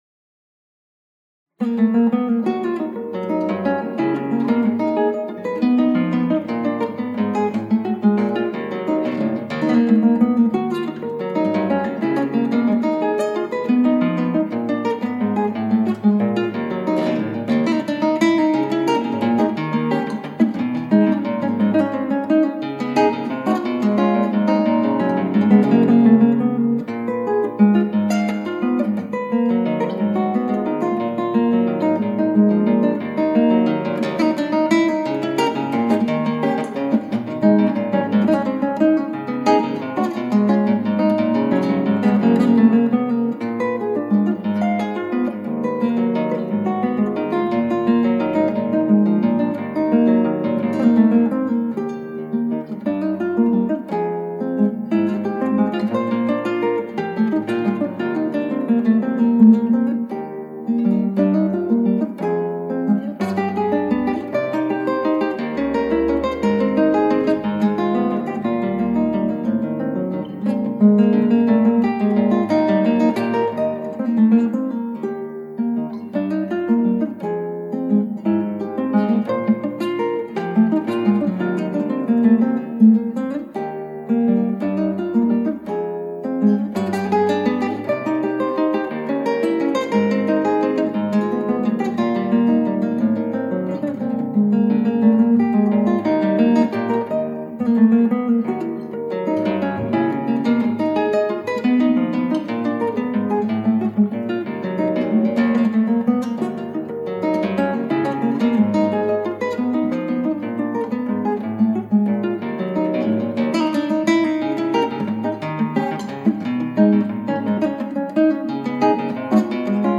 クラシックギター　ストリーミング　コンサート
この曲ワルツなのにワルツじゃない？
変拍子なんだよねこの曲。３／４ではあるのですが、２の４で弾く部分と３の３で弾く部分とあるんだよ。